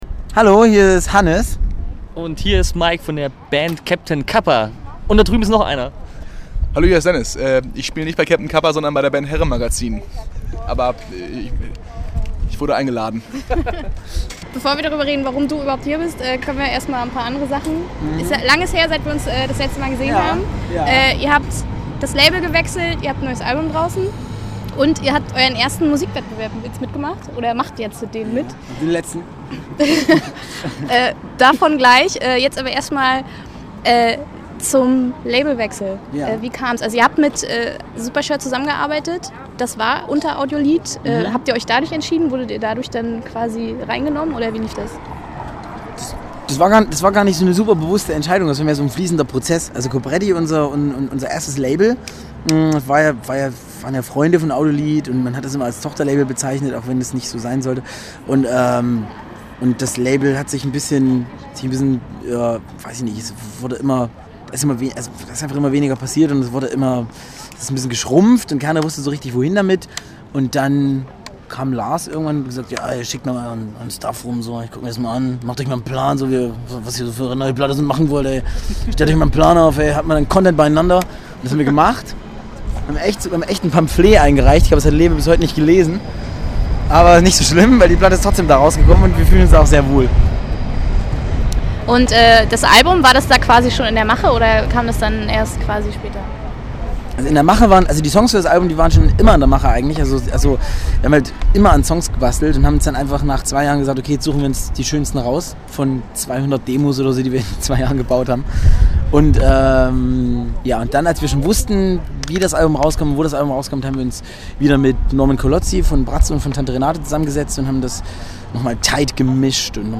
Captain Capa Interview Teil 1: